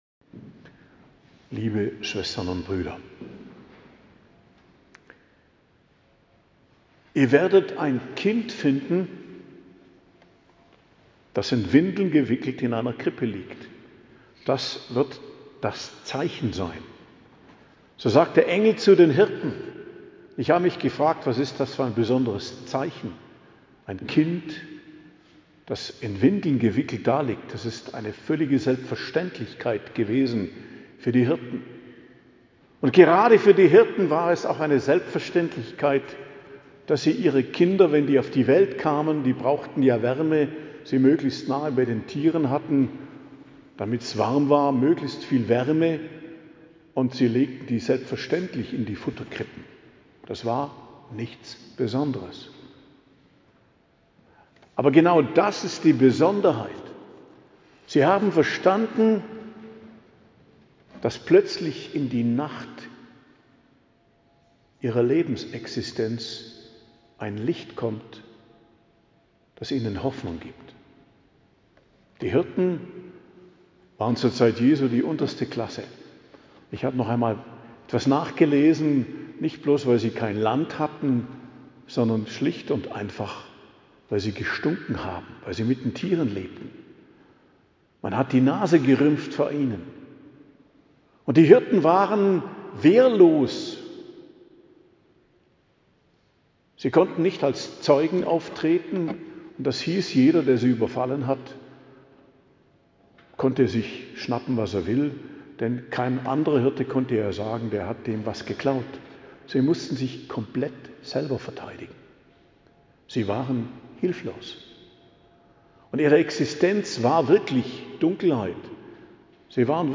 Predigt zu Weihnachten - Hochfest der Geburt des Herrn - In der Heiligen Nacht, 24.12.2025 ~ Geistliches Zentrum Kloster Heiligkreuztal Podcast